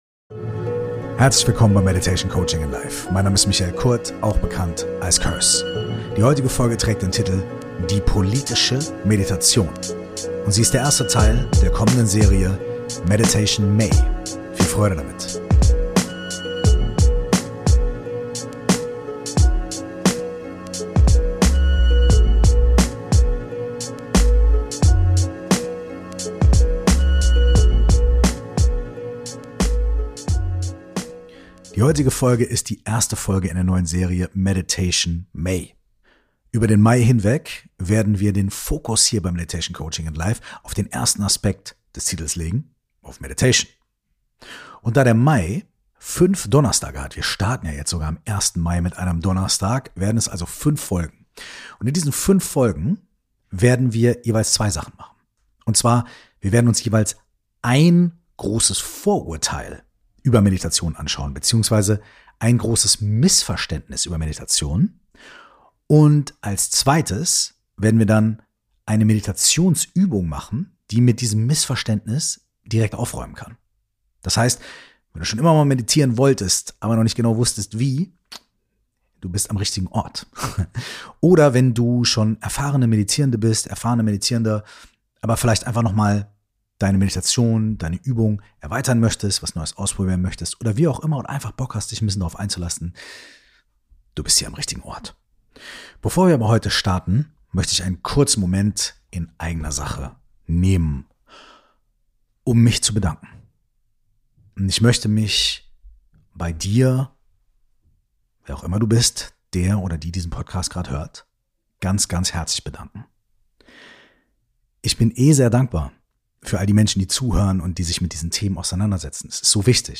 In diesen fünfteiligen Mini-Serie räumt Curse jede Woche mit einem Missverständnis über das Meditieren auf und widmet sich der Praxis, in Form einer geführten Meditation.